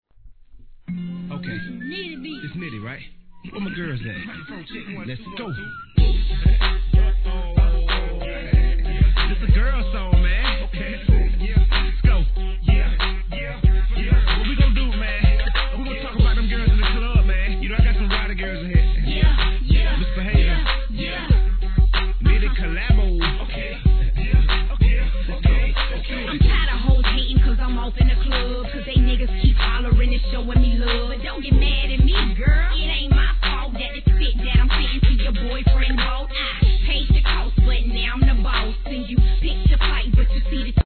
HIP HOP/R&B
歯切れのイイドラムラインとフックの掛声がフロア受けばっちりでした!